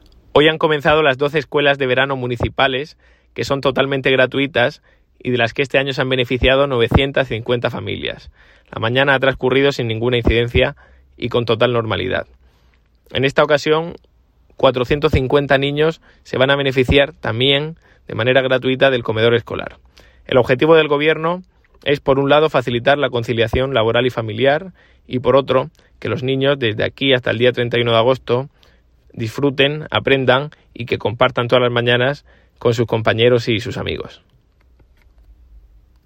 Enlace a Declaraciones de Ignacio Jáudenes sobre el inicio de las Escuelas de Verano